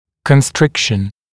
[kən’strɪkʃn][кэн’стрикшн]сжатие, сокращение, стягивание, сужение